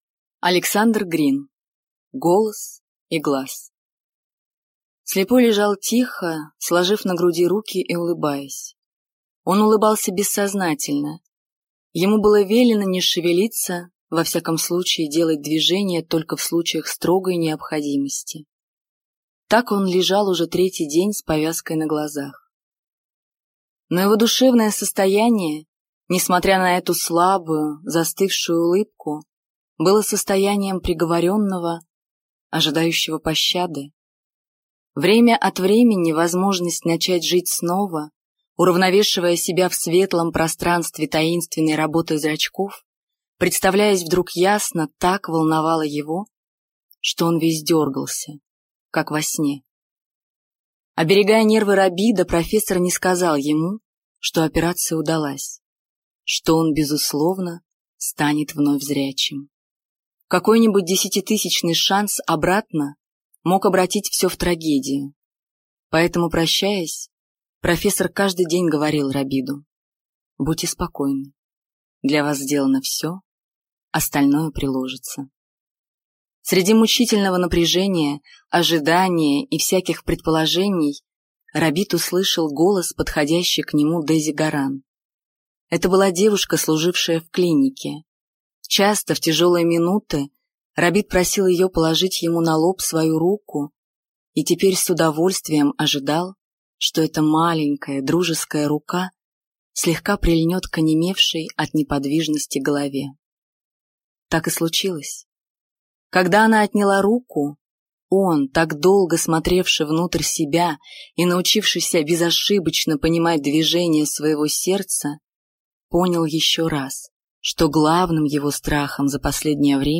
Аудиокнига Голос и глаз | Библиотека аудиокниг